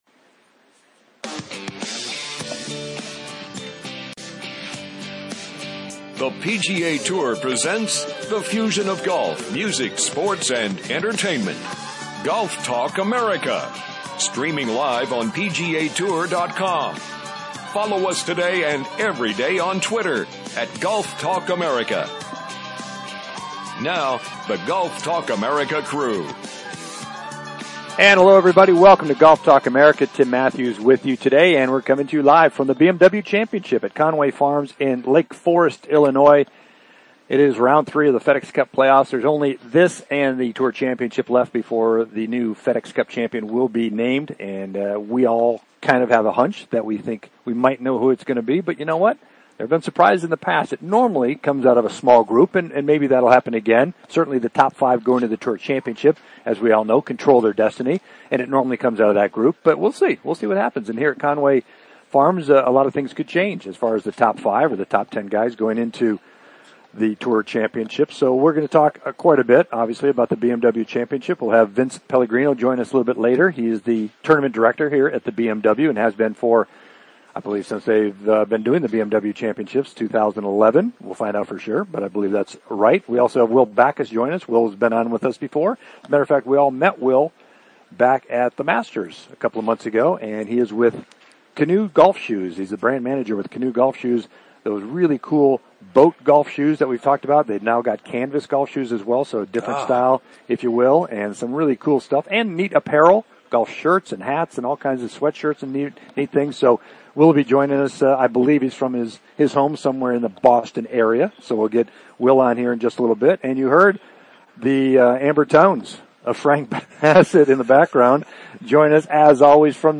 "LIVE" FROM THE BMW CHAMPIONSHIP